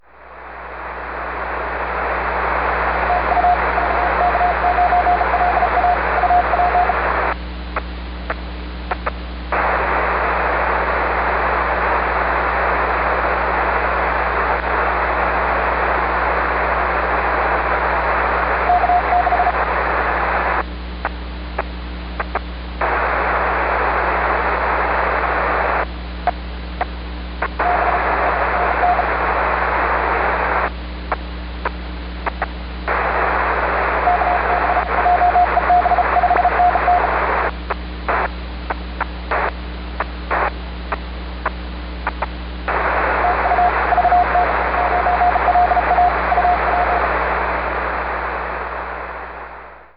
Working VK9LA on 30m CW
Here’s the audio of the CW QSO: